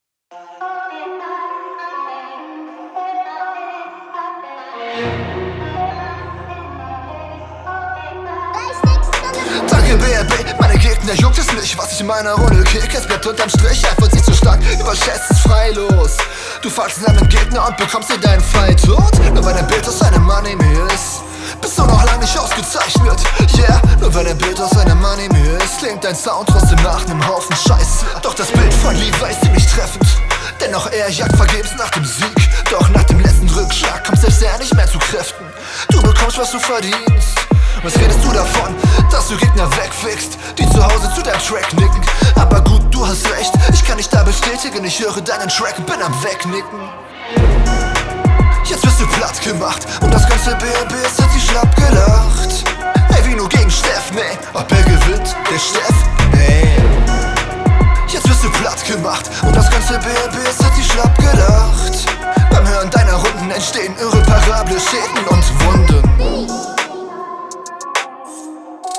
Flow ist wieder nicht ganz sauber auf dem Beat und leidet unter kleineren Schönheitsfehlern.